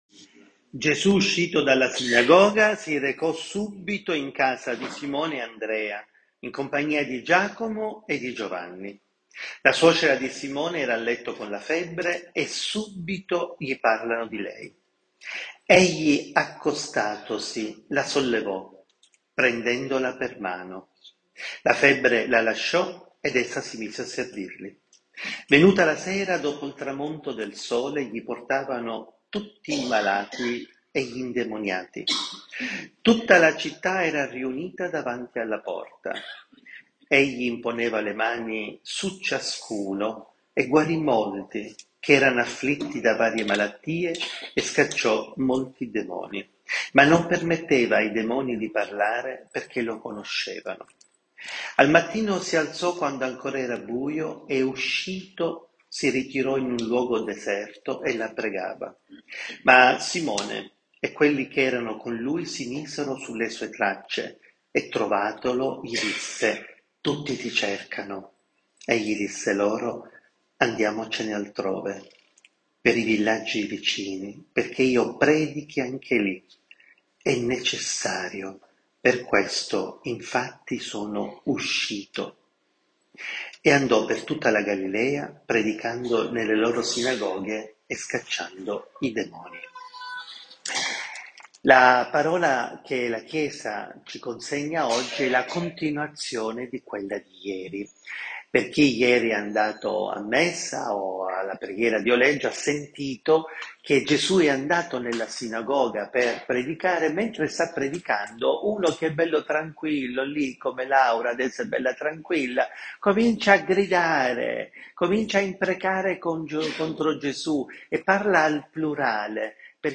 suocera , guarigione , preghiera , intercessione , libertà , predicazione , imposizione